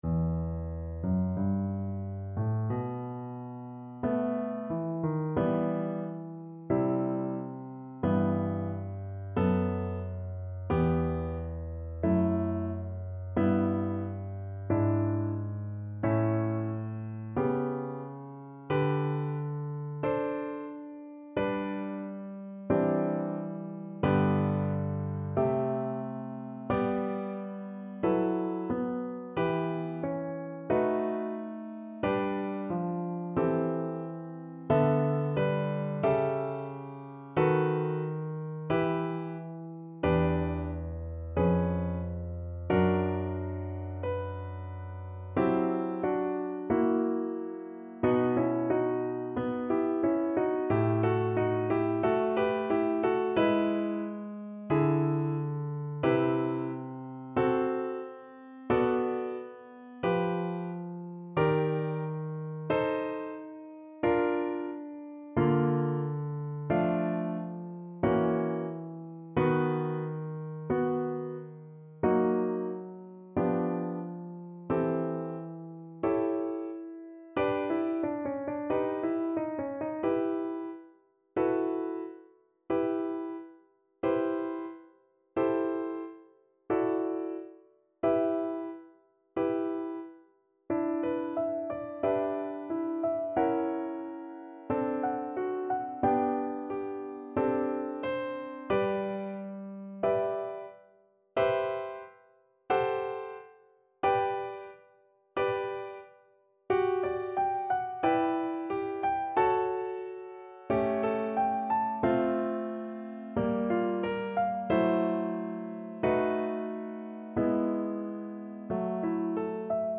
Adagio, molto tranquillo (=60) =45
Classical (View more Classical Cello Music)